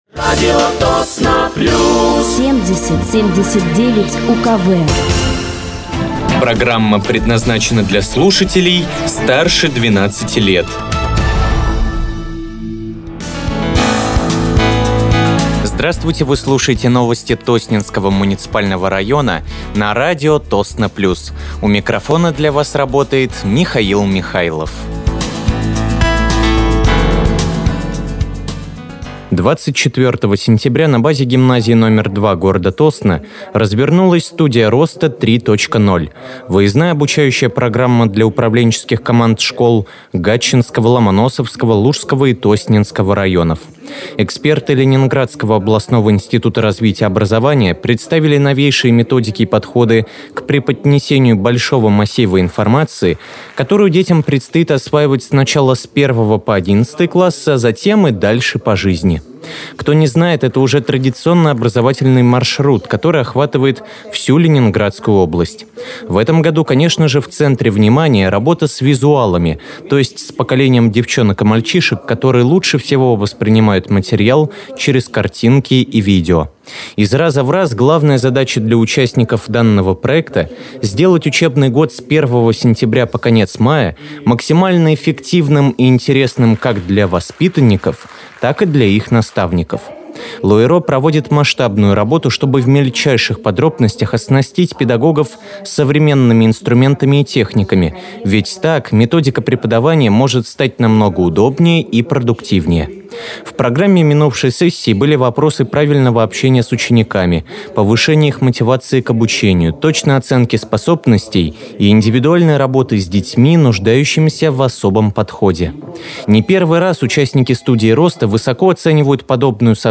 Выпуск новостей Тосненского муниципального района от 29.09.2025
Вы слушаете новости Тосненского муниципального района на радиоканале «Радио Тосно плюс».